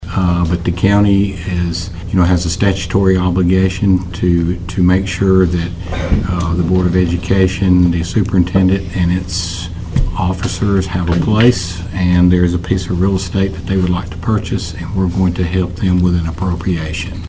Commission Chairman Burgess had these comments about the transaction: